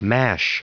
Prononciation du mot mash en anglais (fichier audio)